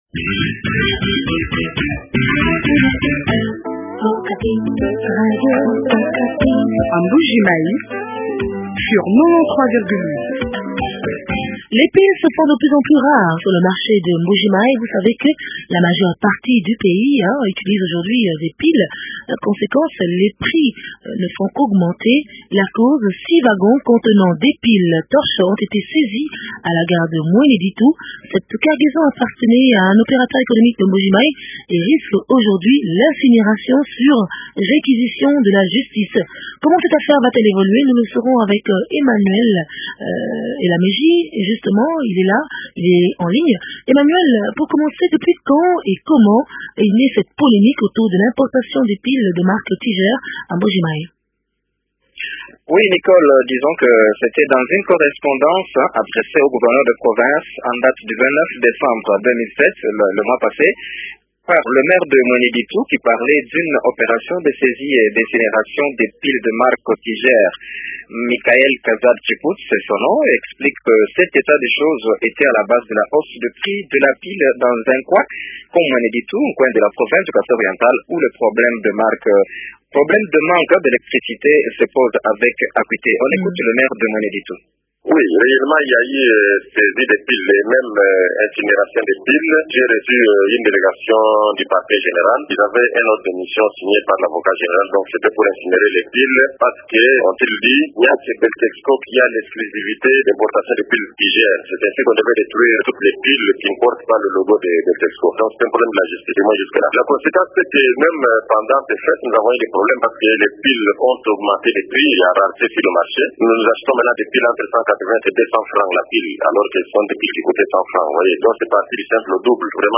Beltexco prétend avoir le monopole sur les piles de marque Tiger, mais Zecodiam rejette les affirmations de Beltexco. Le ministre de l’industrie tranche sur cette polémique au téléphone